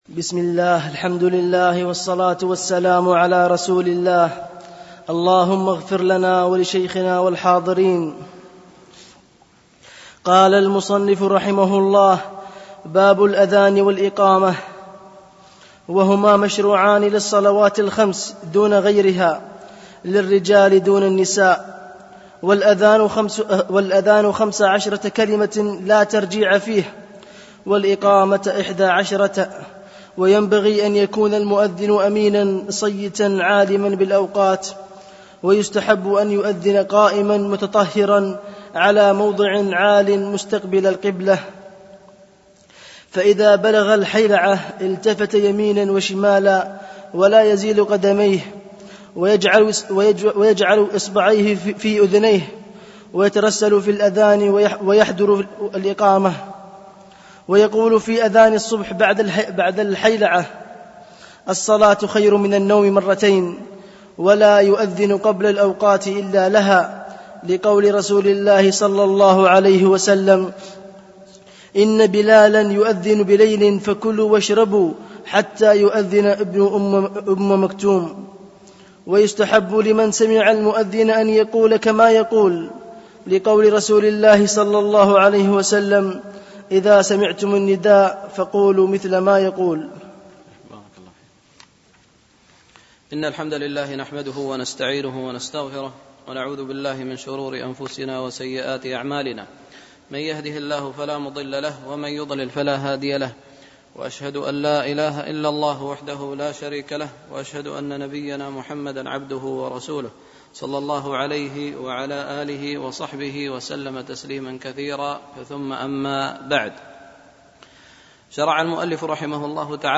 دروس مسجد عائشة